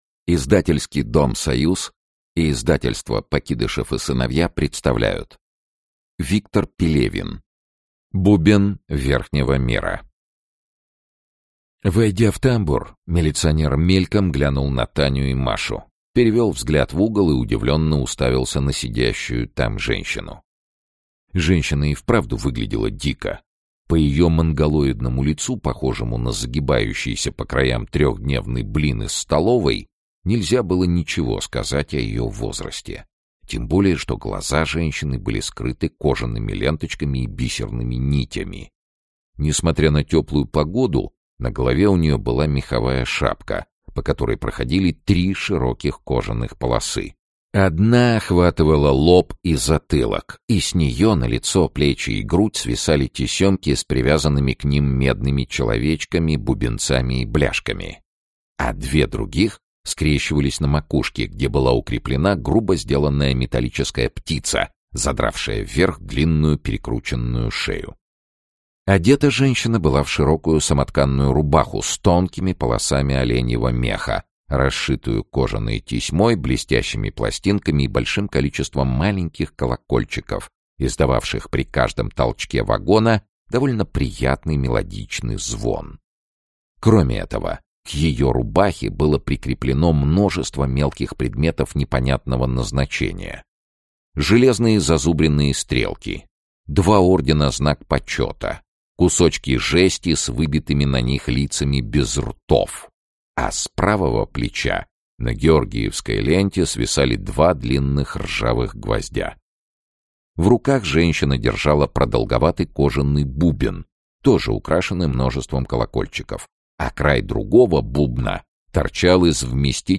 Аудиокнига Бубен Верхнего мира. Бубен Нижнего мира | Библиотека аудиокниг
Бубен Нижнего мира Автор Виктор Пелевин Читает аудиокнигу Сергей Чонишвили.